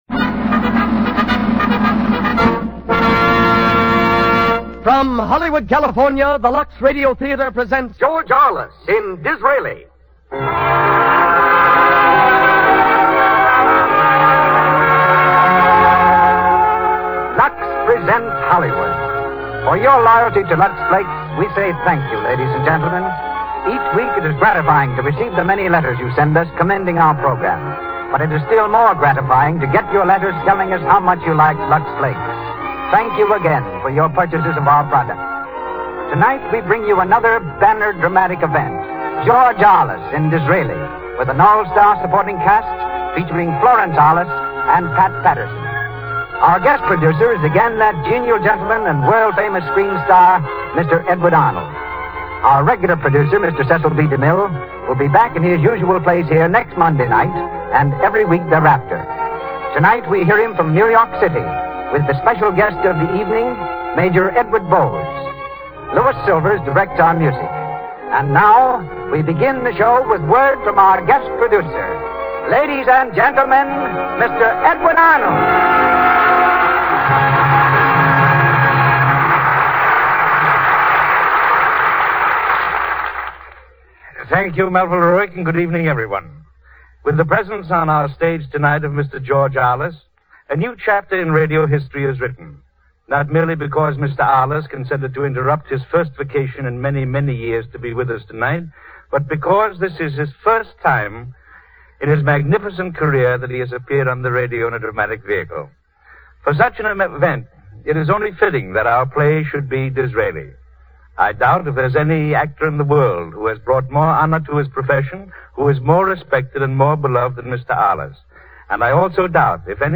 On January 17, 1938, George Arliss made his dramatic radio debut on the CBS network with DISRAELI. This live broadcast on the Lux Radio Theater was heard all over the world and brought to the microphone much of the cast of the 1929 film version including Florence Arliss, Ivan Simpson, Doris Lloyd, and David Torrence.